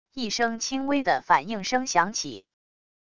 一声轻微的反应声响起wav音频